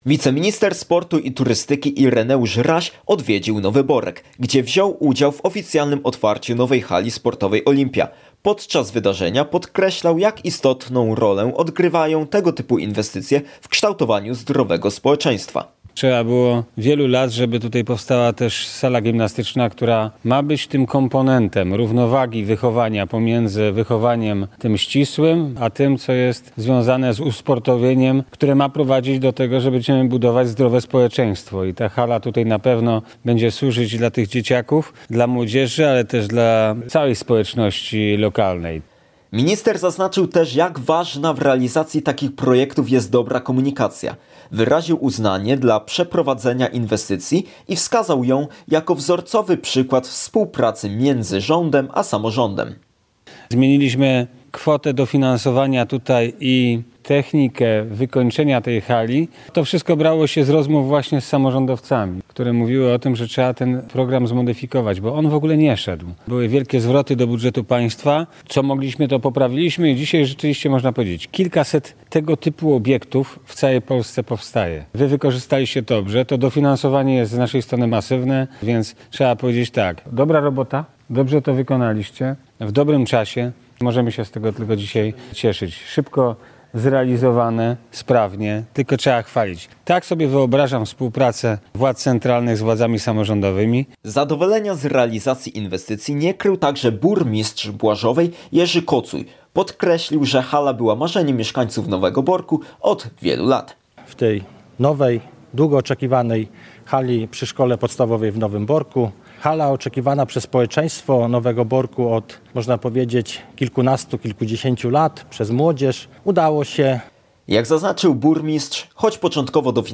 Wiceminister sportu i turystyki Ireneusz Raś odwiedził Nowy Borek (pow. rzeszowski), gdzie uczestniczył w otwarciu nowej hali sportowej „Olimpia”.